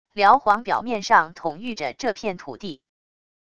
辽皇表面上统御着这片土地wav音频生成系统WAV Audio Player